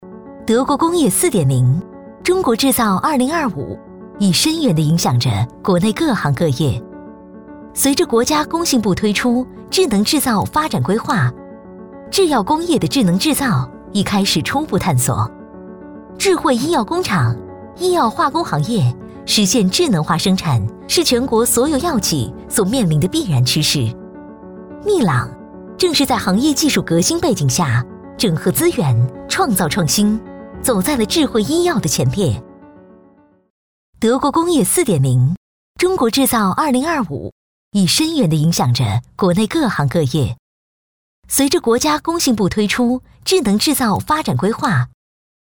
轻松自然 企业宣传配音
时尚甜美女音。温暖讲述。